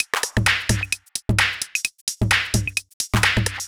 130 Driller Killer Halfnobd.wav